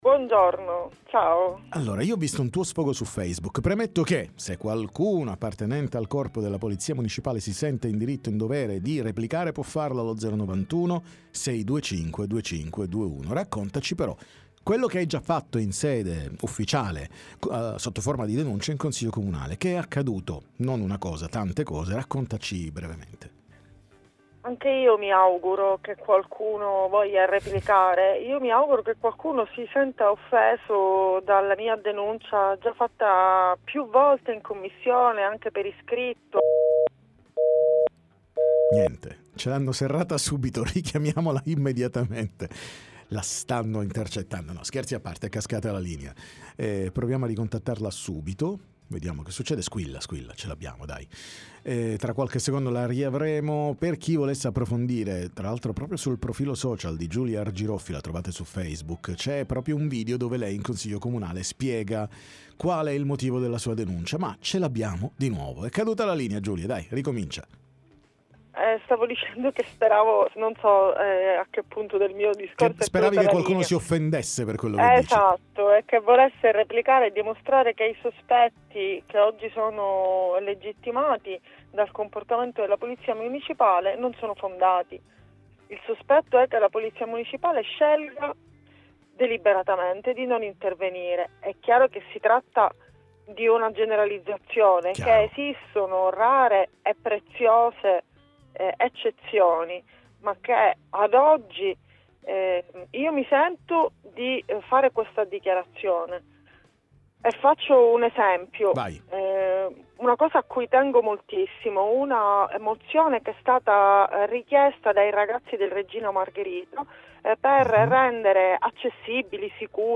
Accuse alla Polizia Municipale da parte di Giulia Argiroffi, cons. com. gruppo OSO, ne parliamo con lei